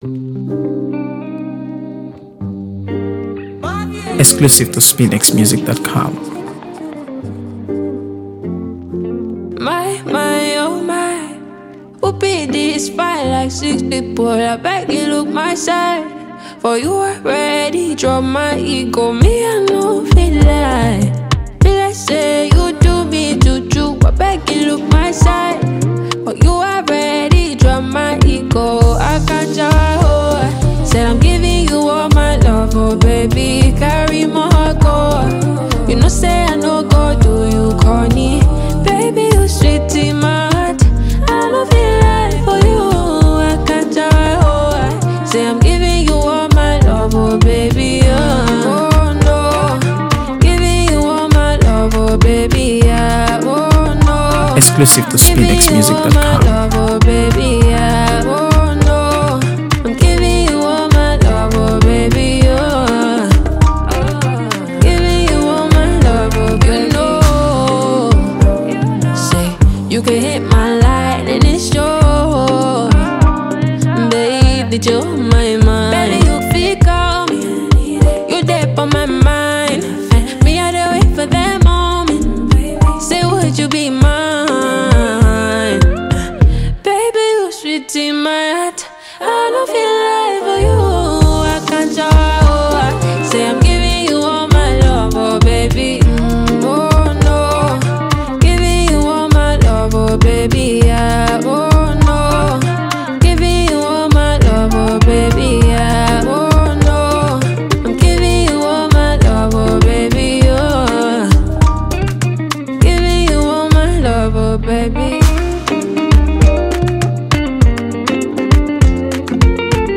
AfroBeats | AfroBeats songs
rich vocals, infused with raw passion